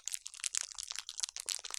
Large Fire Crackle 2.wav